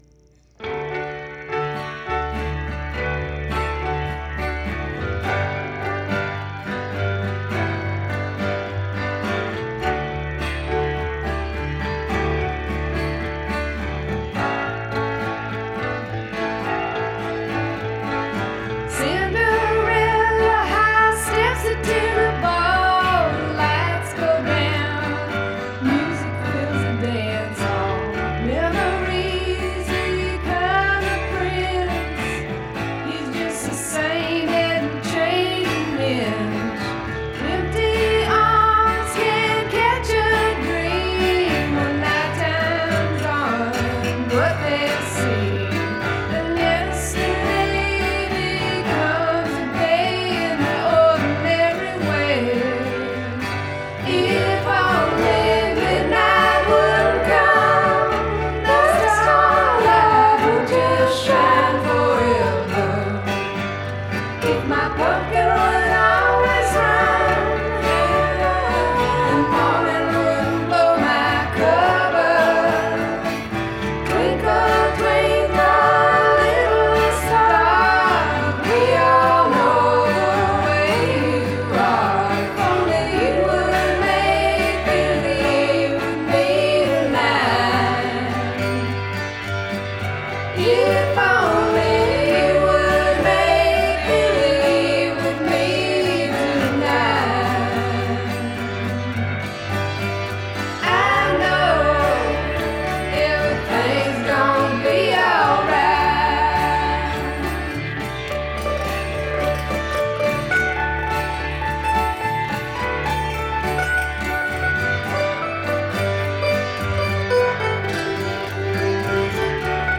voices, piano, guitar
mandolin, bass